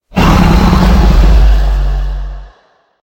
dragon_growl2.ogg